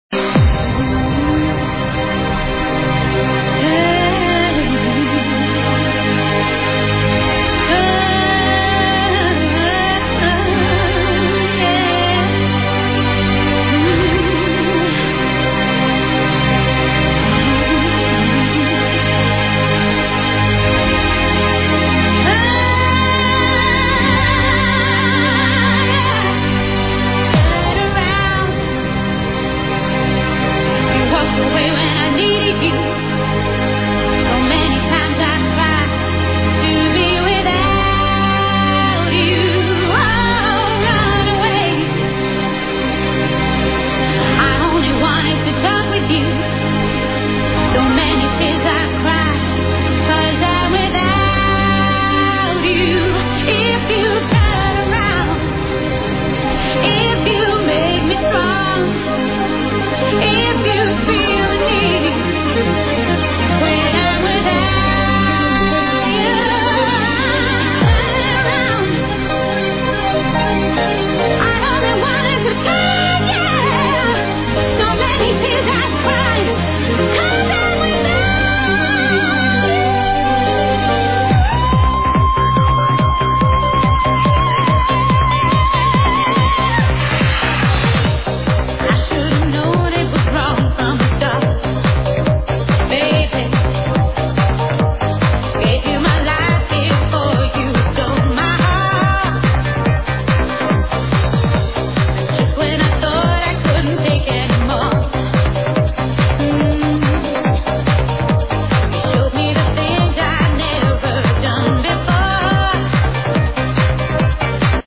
tune with female vocals that say "turn around..."